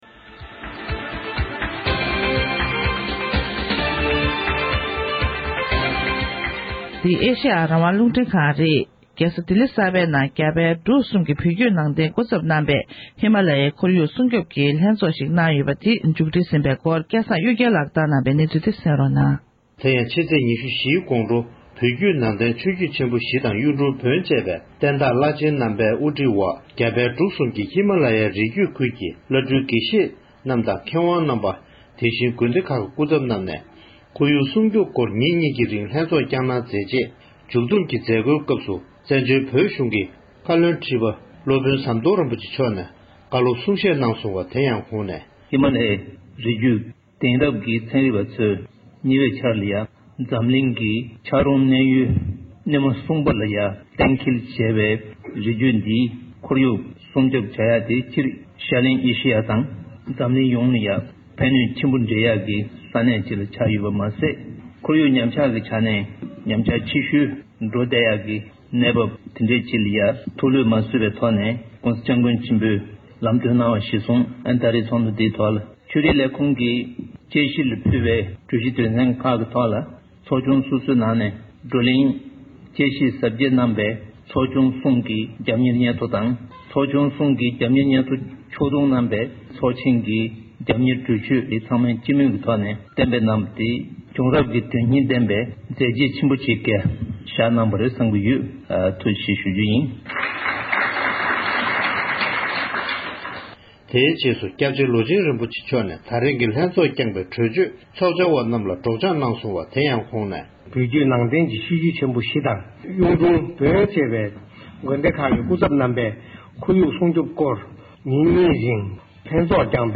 བཀའ་ཁྲི་མཆོག་ནས་རྒྱ་གར་རྒྱལ་ས་ལྡི་ལིའི་ནང་ཁོར་ཡུག་སྲུང་སྐྱོབ་ཀྱི་ལྷན་ཚོཊ་མཇུག་སྒྲིལ་གྱི་མཛད་སྒོའི་ཐོག་གསུང་བཤད།